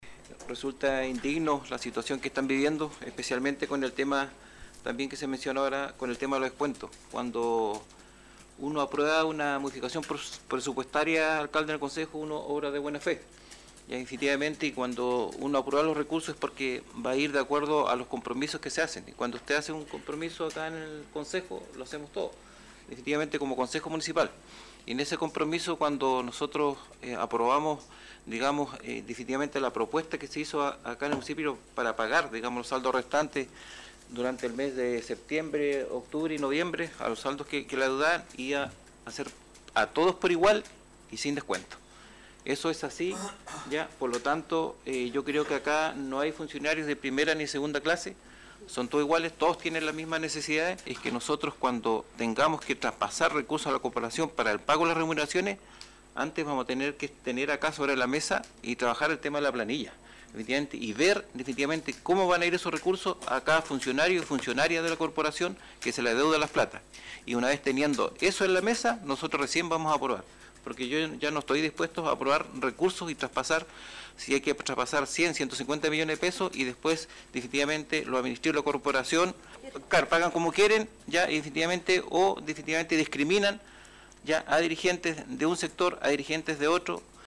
Desde la mesa del concejo, Alex Muñoz habló de acciones irregulares toda vez que en su momento, se aprobó por el organismo la transferencia de los recursos a la corporación, que sin embargo ahora retrasa los pagos o los parcializa, perjudicando a los afectados, y causando mayor sufrimiento del que ya ha habido durante todo este año.